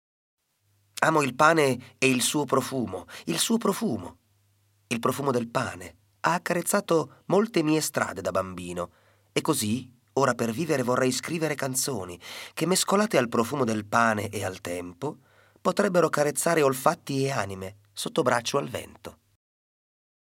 Poesia letta